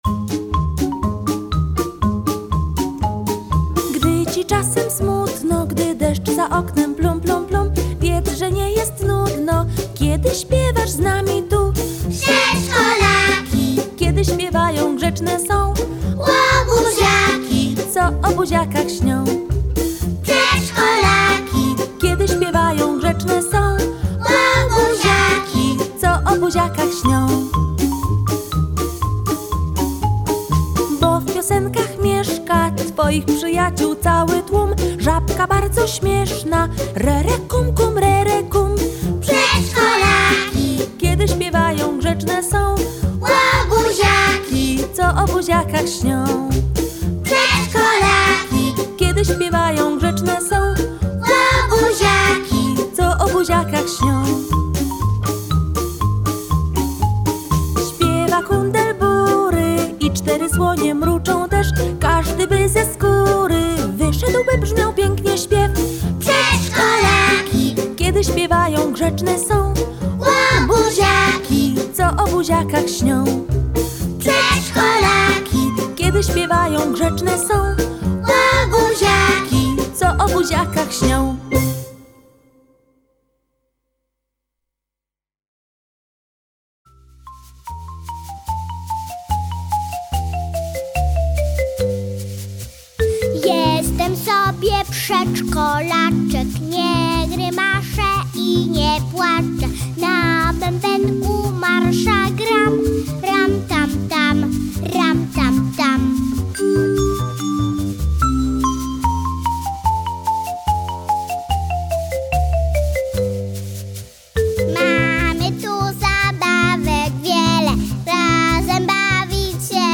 akompaniuje chórek dziecięcy